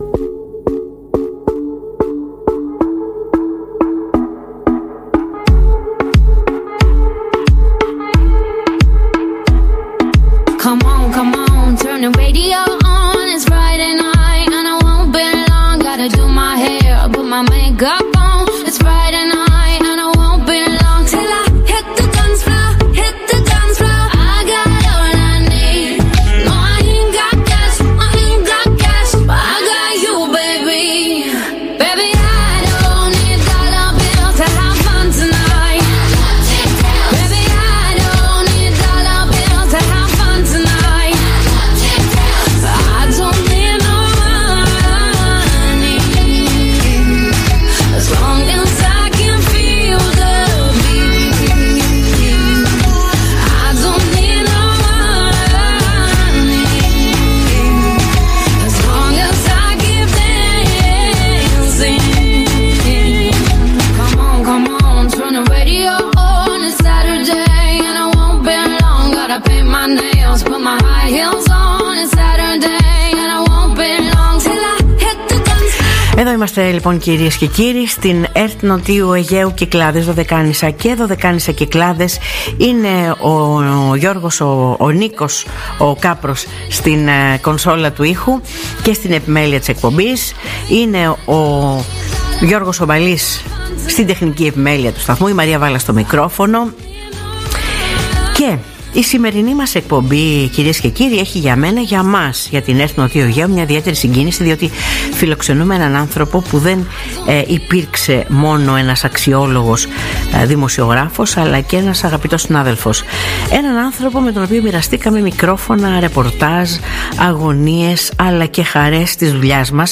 Ο δήμαρχος του νησιού, κ. Ευάγγελος Κόττορος, στην εκπομπή ΡΑΔΙΟΠΛΟΕΣ αναφέρεται στη μεγάλη απώλεια και στο έργο του. Επίσης, Ο ίδιος, αναλύει επίσης, τη σειρά προβλημάτων και τις προκλήσεις που αντιμετωπίζουν οι κάτοικοι σε κάθε μικρό νησί.